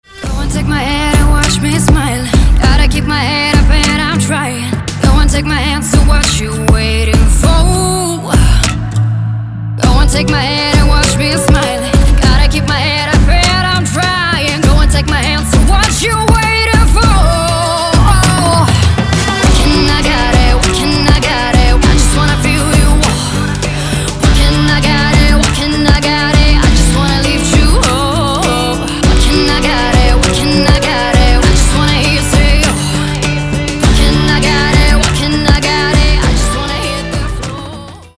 • Качество: 224, Stereo
поп
женский вокал
dance
vocal